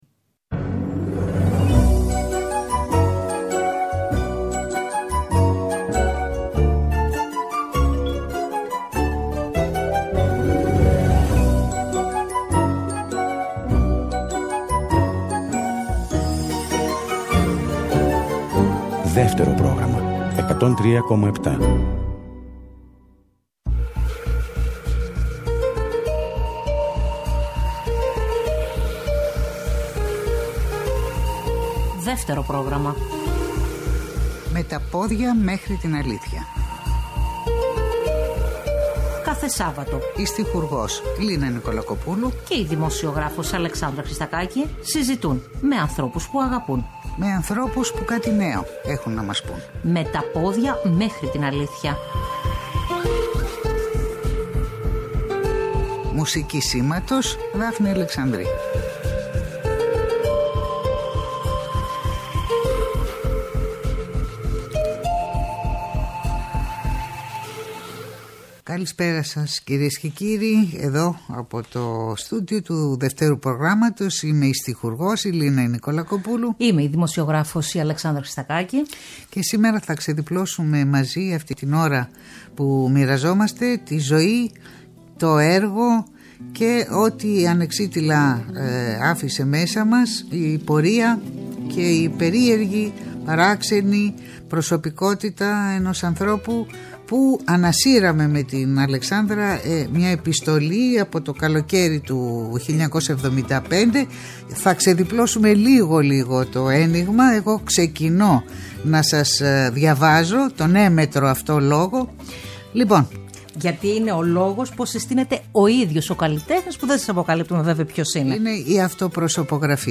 Στην εκπομπή ακούγονται ηχητικά αποσπάσματα από συνεντεύξεις του Άκη Πάνου στην ΕΡΤ και συγκεκριμένα ηχητικό απόσπασμα από τo ντοκυμαντέρ “Άκης Πάνου” το οποίο σκηνοθέτησε ο Βασίλης Βαφέας το 1976 στο πλαίσιο της εκπομπής “Παρασκήνιο”. Τέλος, διαβάζεται η έμμετρη αλληλογραφία Άκη Πάνου – Μάνου Χατζηδάκι, με τις ιδιότητες του τραγουδοποιού και του επικεφαλής του Τρίτου Προγράμματος το 1975 και με αφορμή την διαμαρτυρία του Άκη Πάνου προς τον Μάνο Χατζηδάκι για τον αποκλεισμό των τραγουδιών του από τα ερτζιανά.